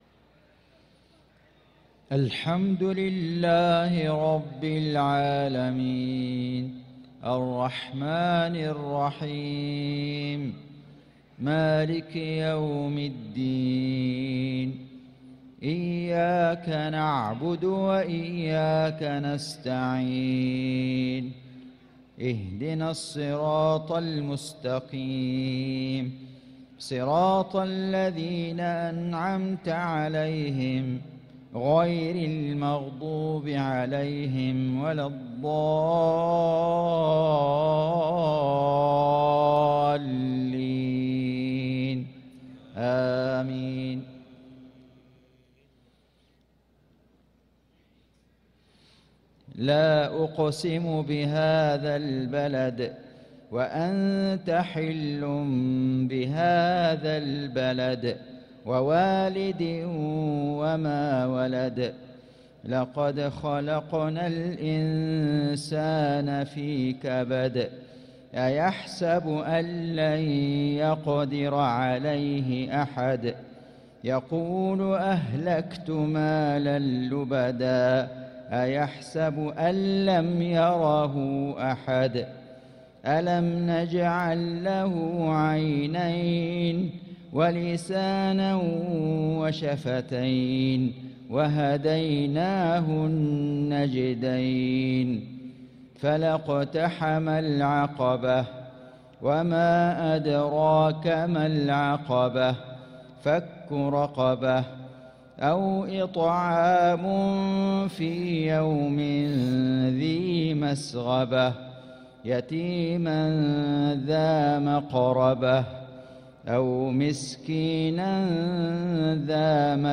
صلاة العشاء للقارئ فيصل غزاوي 2 شوال 1445 هـ